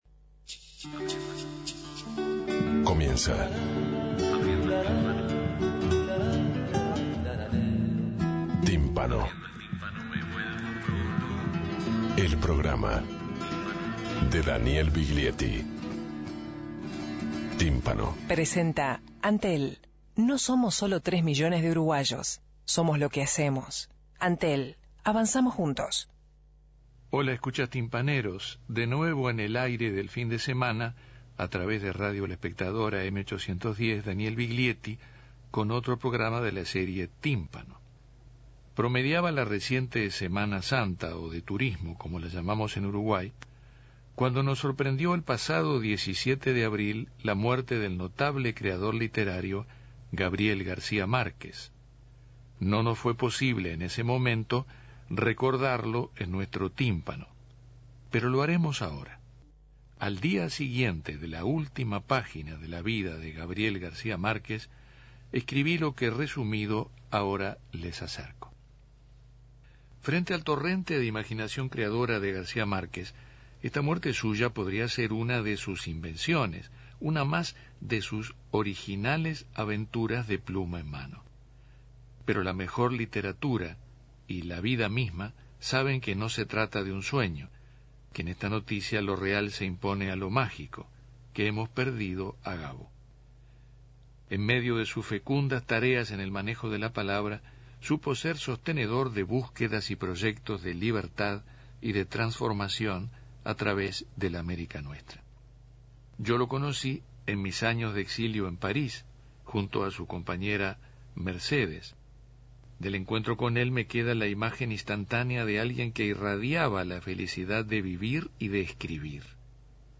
Tímpano recuerda al escritor y rodea con música sus palabras con motivo de recibir el el premio Nobel de literatura 1982.